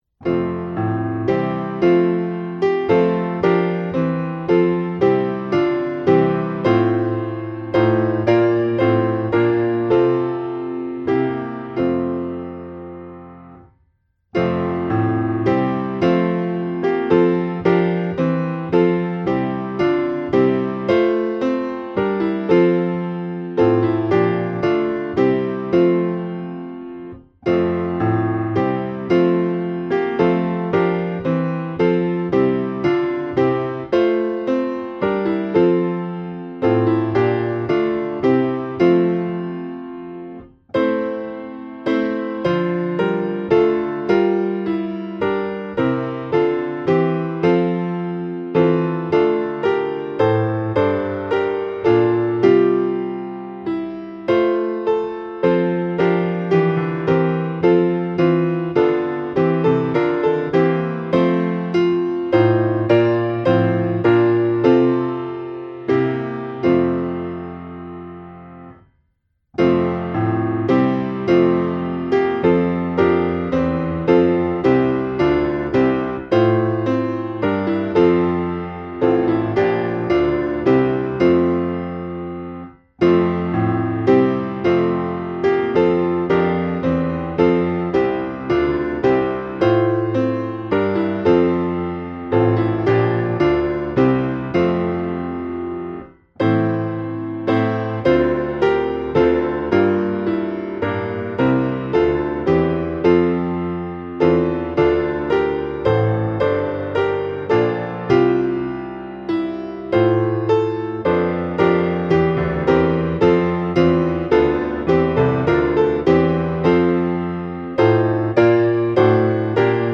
2004-Praise_the_Source_of_Faith_and_Learning-piano.mp3